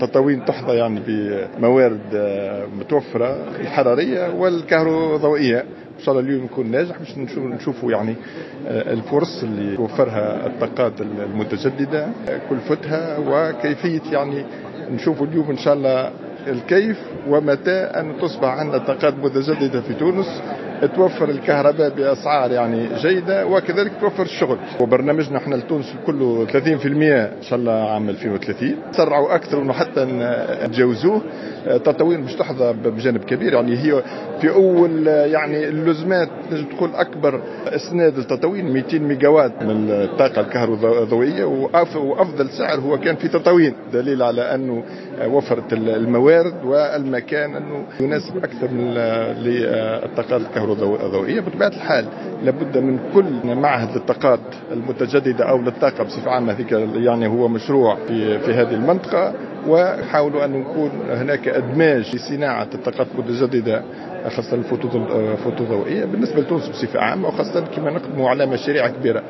تطاوين: يوم اعلامي حول واقع وآفاق الطاقات المتجددة في تونس